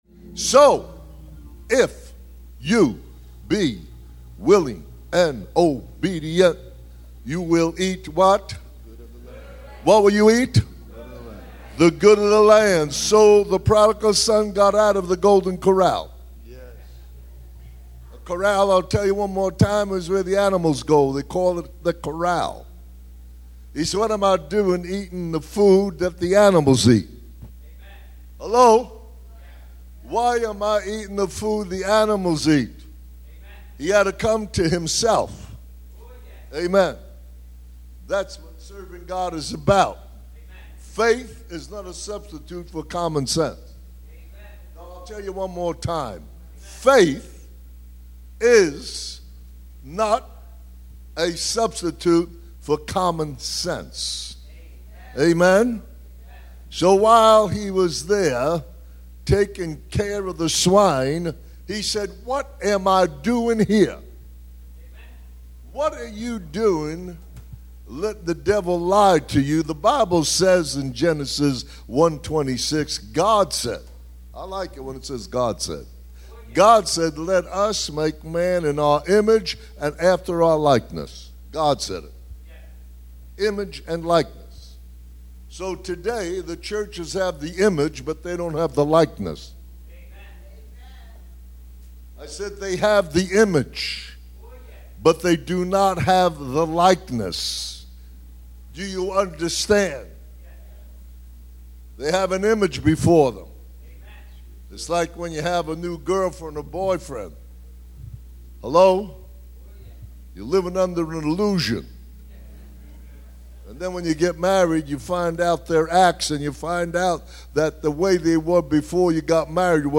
Past Weekly Sermons